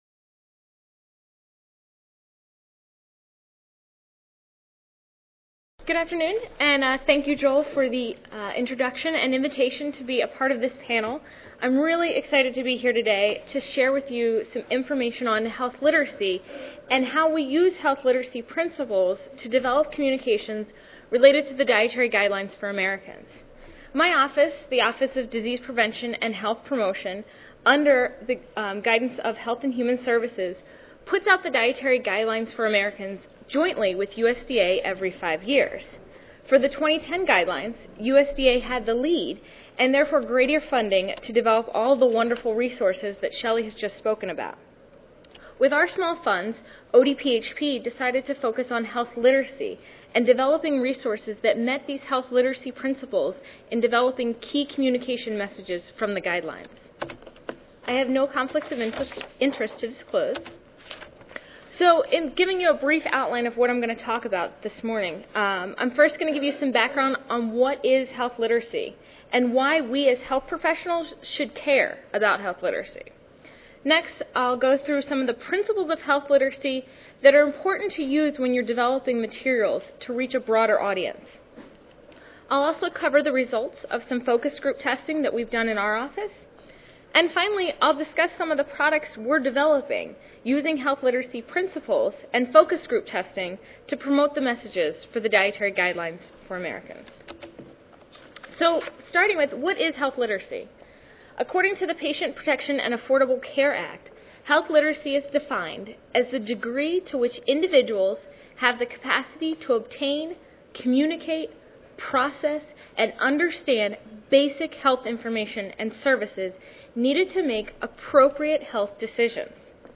3237.0 Communicating and Applying the 2010 Dietary Guidelines for Americans: Resources for Public Health Practice Monday, October 31, 2011: 12:30 PM Oral The 2010 Dietary Guidelines for Americans provide evidenced-based nutrition guidance for developing educational materials, nutrition policy and programs. A panel of federal employees will discuss new Federal nutrition education resources and worksite nutrition standards and sustainability guidelines that are based on the Dietary Guidelines.